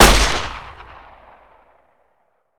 greaseGun.ogg